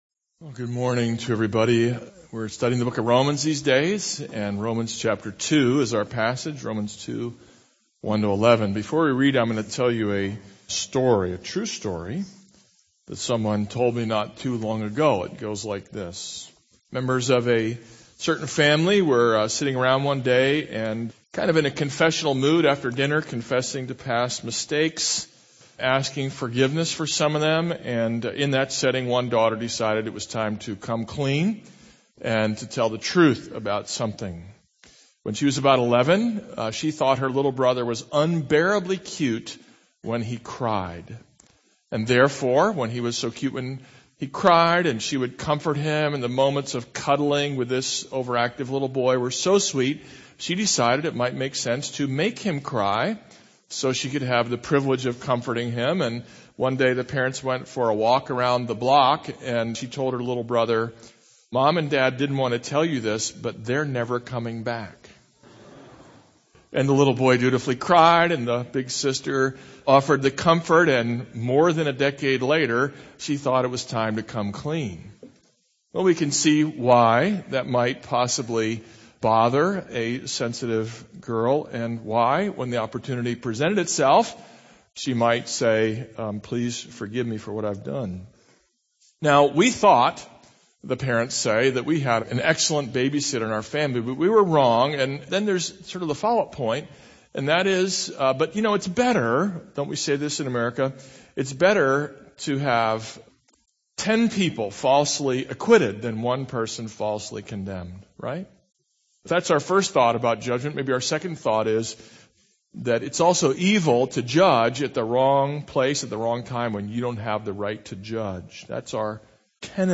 This is a sermon on Romans 2:1-11.